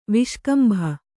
♪ viṣkambha